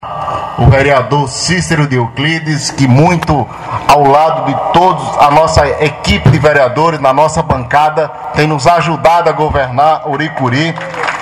Na última sexta-feira, 11 de fevereiro, durante a inauguração de mais uma rua pavimentada no centro de Ouricuri, o vereador Cícero de Euclides, esteve presente ao lado de outros colegas de bancada onde participou efetivamente do evento, onde foi elogiado pelo vereador Alex, além do prefeito Ricardo Ramos.